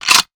weapon_foley_pickup_25.wav